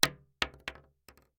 Bullet Shell Sounds
shotgun_wood_2.ogg